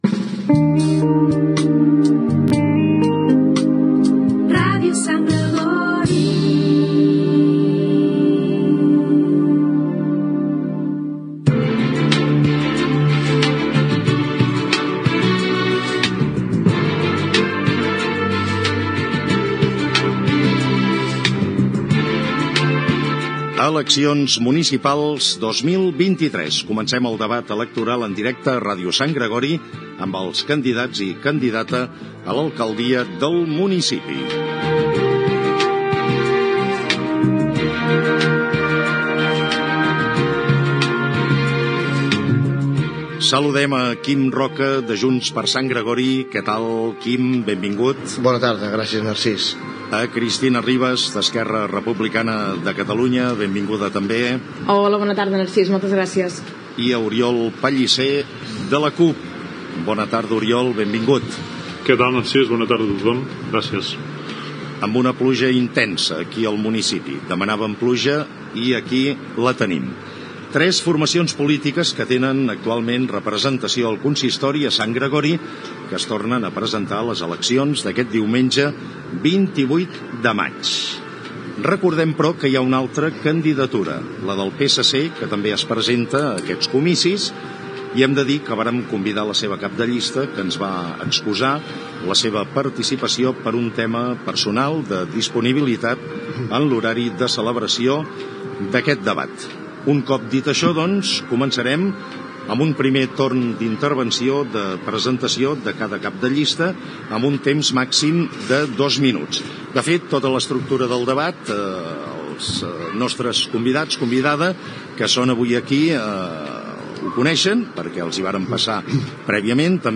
Indicatiu de l'emissora, presentació del debat entre els candidats a les eleccions municipals.
Informatiu
FM